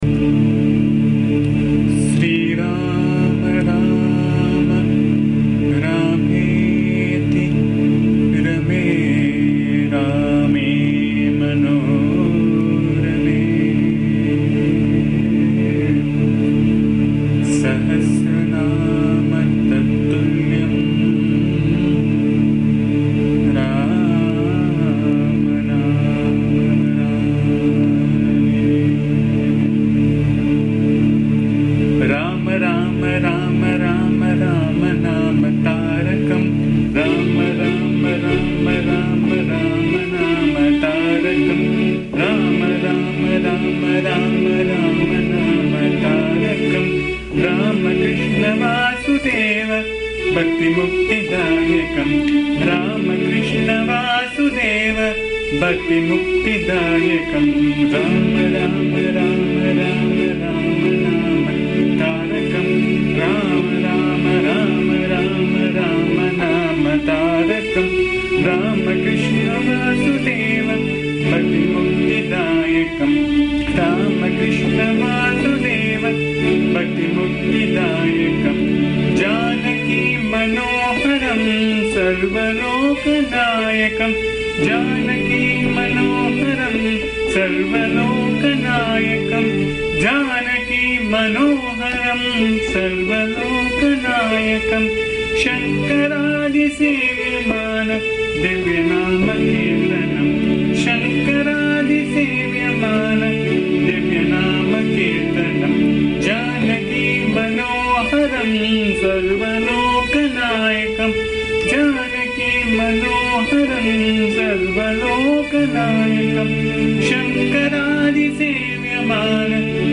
This song is a very beautiful one set in typical Mohana Raaga.
Hence have recorded the song in my voice which can be found here. Please bear the noise, disturbance and awful singing as am not a singer.
AMMA's bhajan song